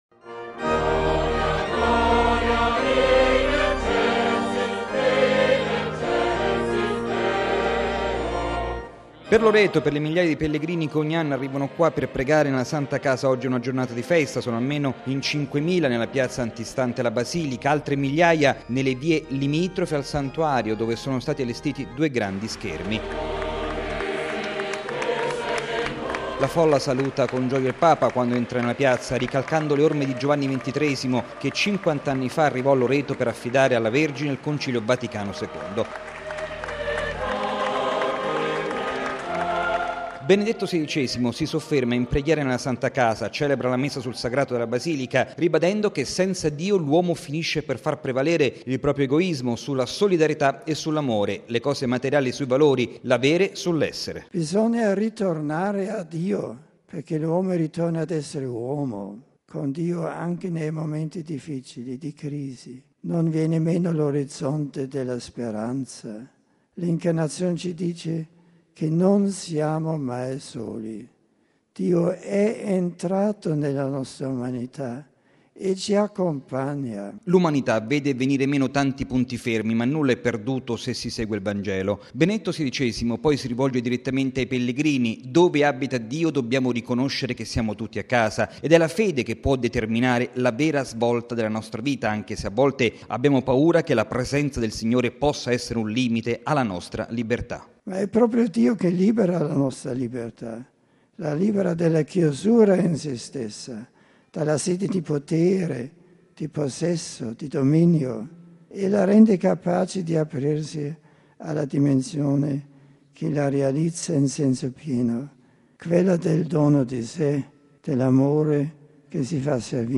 Circa diecimila i fedeli presenti. Il servizio del nostro inviato